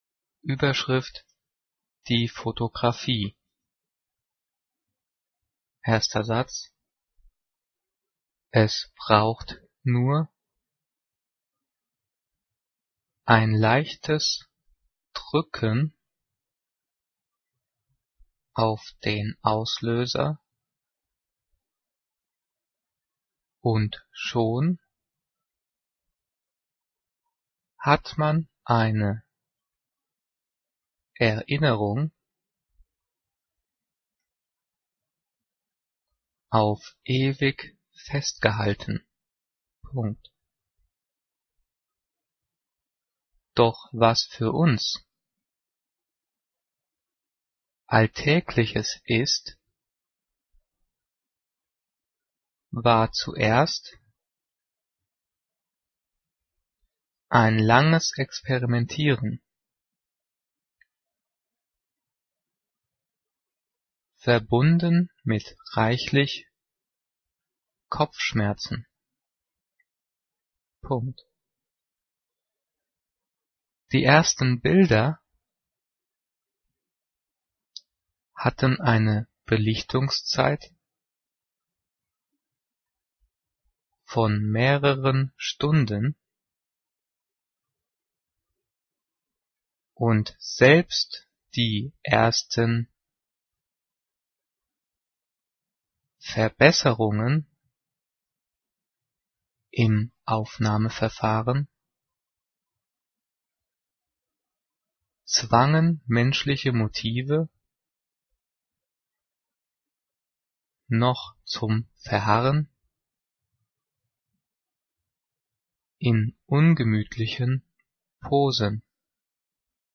Diktiert: